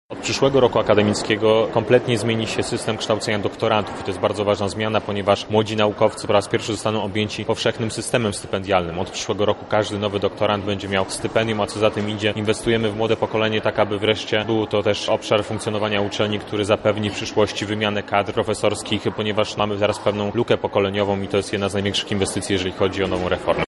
Ważną częścią uroczystości był wykład inaugurujący, który wygłosił podsekretarz stanu w Ministerstwie Nauki i Szkolnictwa Wyższego, Piotr Müller. Jego tematem było dostosowanie się uczelni do tzw. ustawy 2.0 – Konstytucji dla Nauki, która między innymi rozszerza interdyscyplinarność badań naukowych.
O jednej ze zmian którą wprowadza nowe prawo o szkolnictwie wyższym mówi wiceminister Piotr Müller